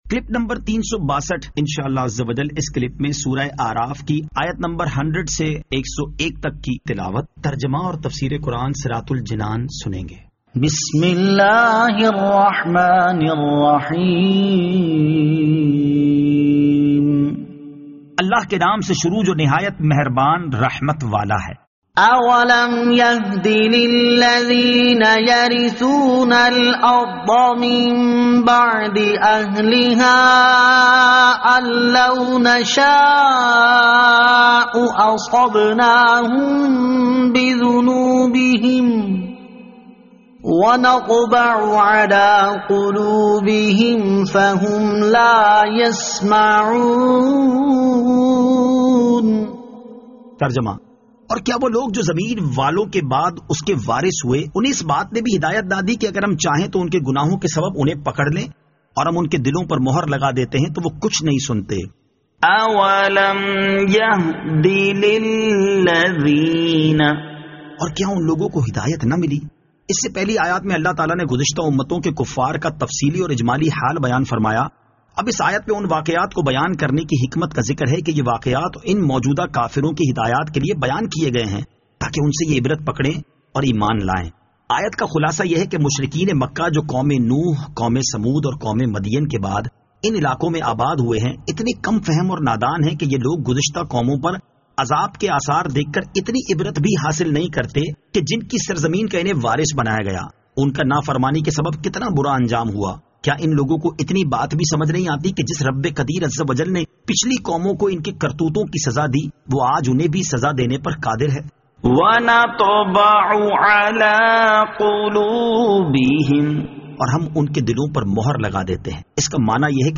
Surah Al-A'raf Ayat 100 To 101 Tilawat , Tarjama , Tafseer
2021 MP3 MP4 MP4 Share سُوَّرۃُ الْاَعْرافْ آیت 100 تا 101 تلاوت ، ترجمہ ، تفسیر ۔